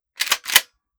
12ga Pump Shotgun - Pump 002.wav